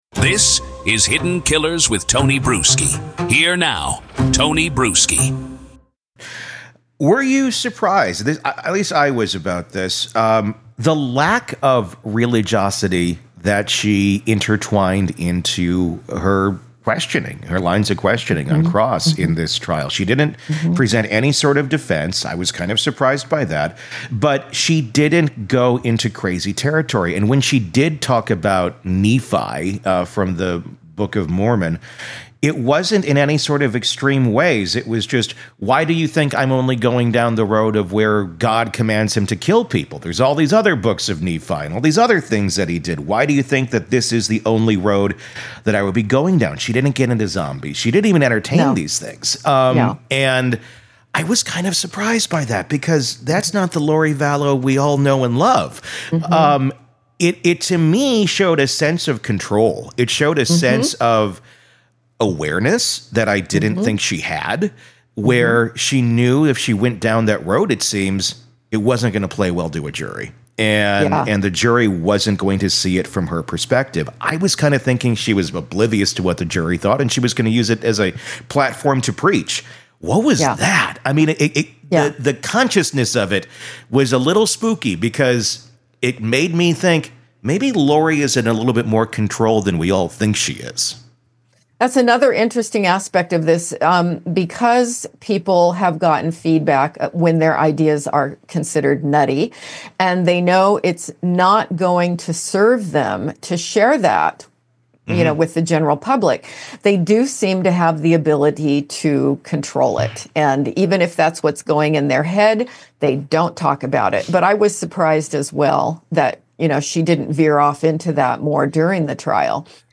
From histrionic behaviors to detached justifications for murder, this conversation reveals a psychological profile that’s even more unsettling than the cult beliefs.